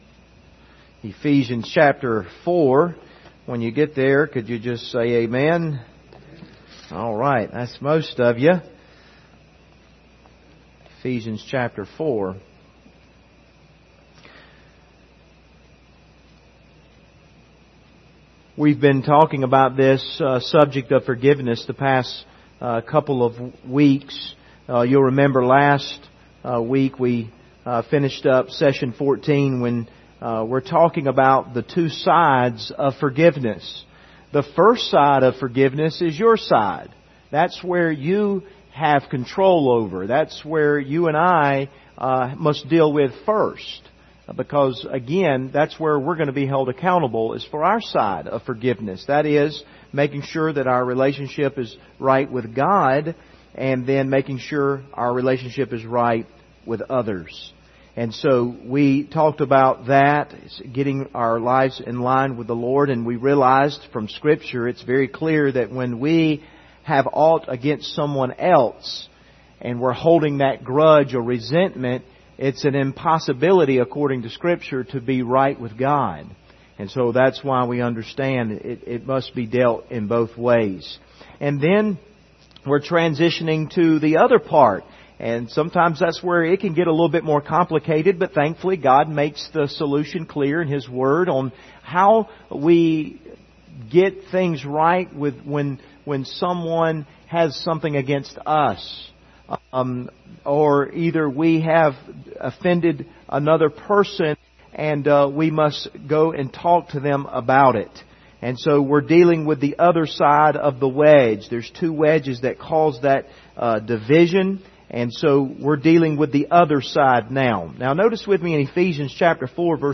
Service Type: Wednesday Evening Topics: forgiveness , relationships